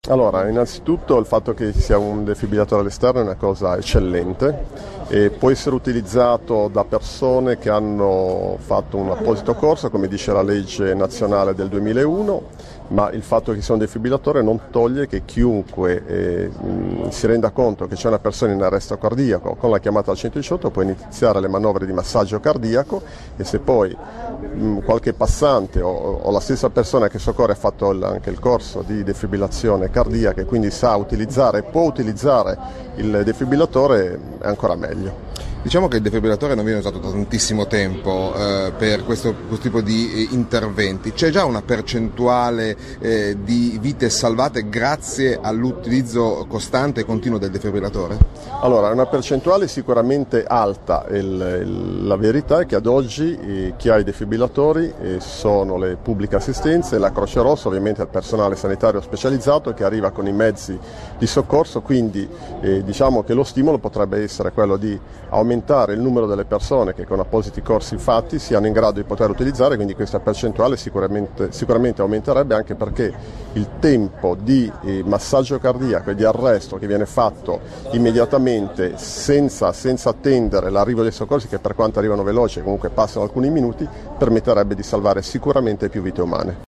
Sanremo: le foto dell'inaugurazione del nuovo defibrillatore pubblico di piazza Colombo